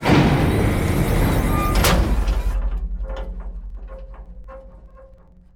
tankdock.wav